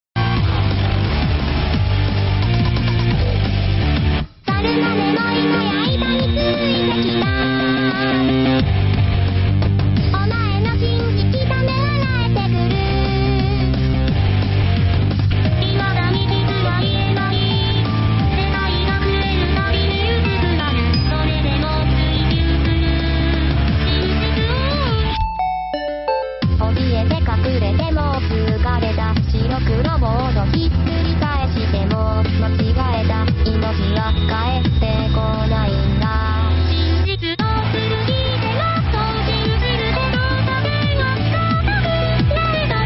涉及术力口本家和泛术力口歌姬